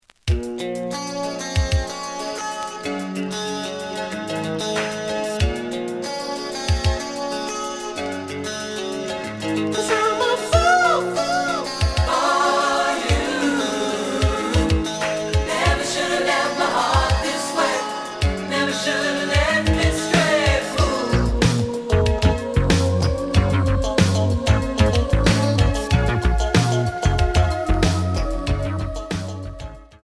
The audio sample is recorded from the actual item.
●Genre: Funk, 80's / 90's Funk
Looks good, but slight noise on both sides.